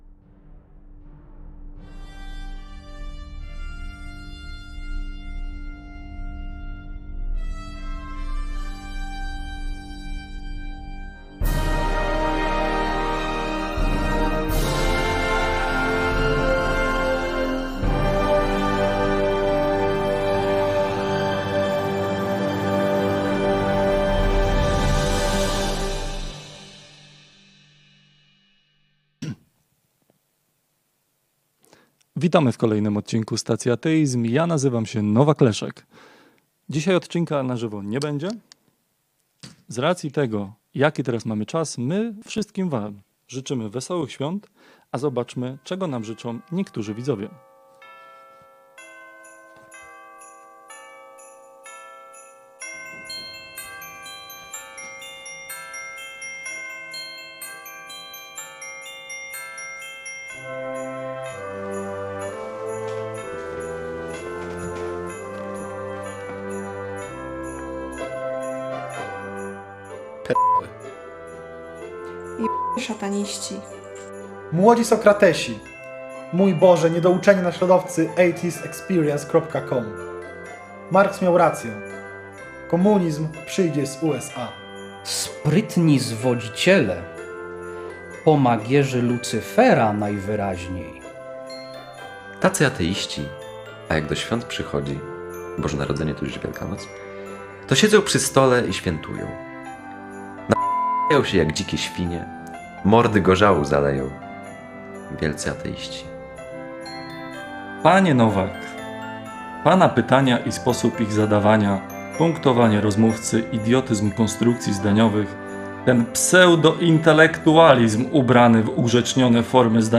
#51. Wesołych Świąt! - ekipa Stacji Ateizm czyta życzenia widzów.m4a